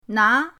na2.mp3